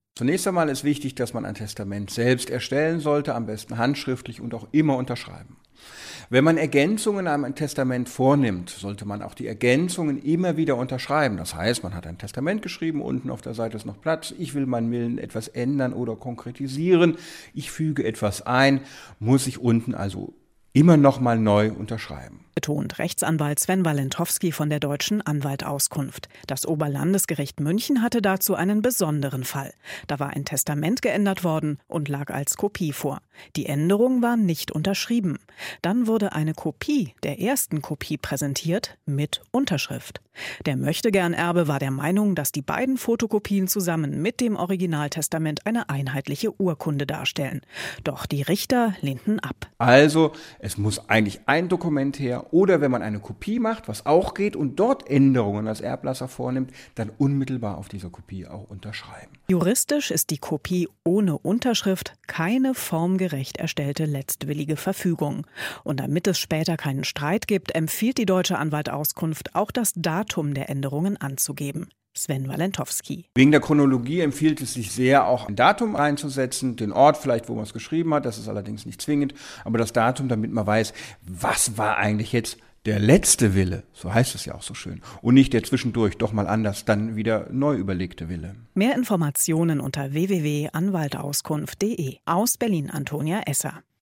Magazin: Testamentsergänzungen auf Kopien stets unterschreiben